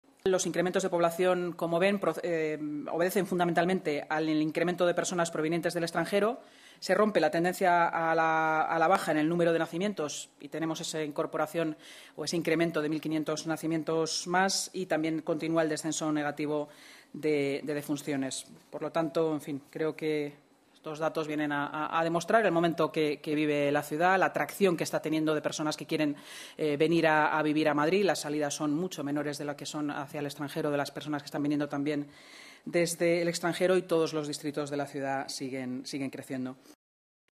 Nueva ventana:Inmaculada Sanz, vicealcaldesa y portavoz municipal